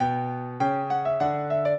piano
minuet2-8.wav